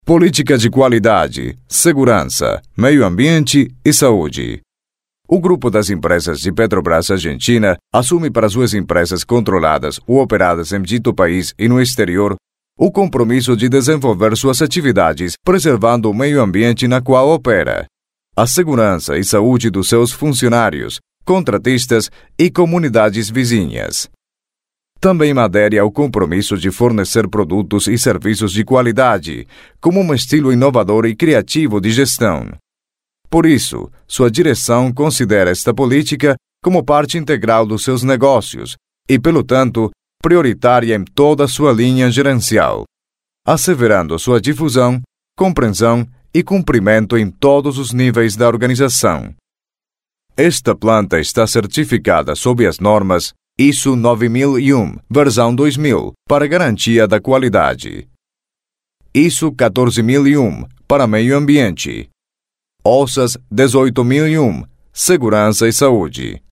LOCUTOR PREMIUM
OBSERVACIONES: Voz versátil para todo tipo de grabaciones en diversos idiomas.
DEMO PORTUGUES: